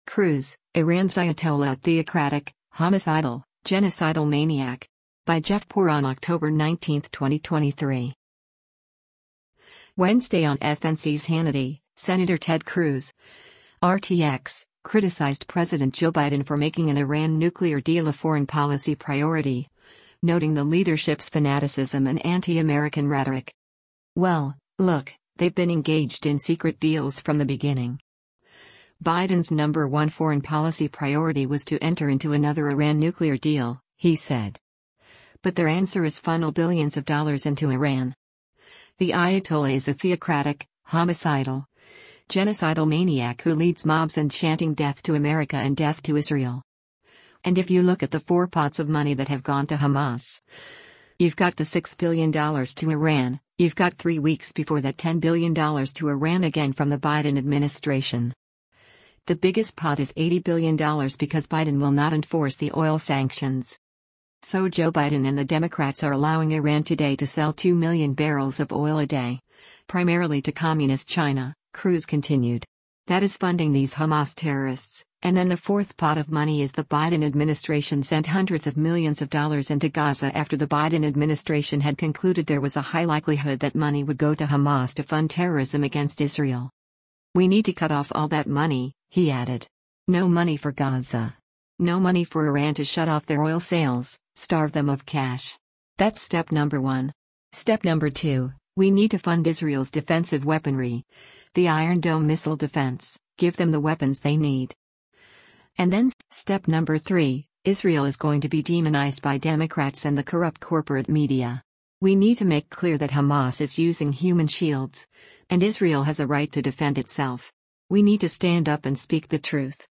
Wednesday on FNC’s “Hannity,” Sen. Ted Cruz (R-TX) criticized President Joe Biden for making an Iran nuclear deal a foreign policy priority, noting the leadership’s fanaticism and anti-American rhetoric.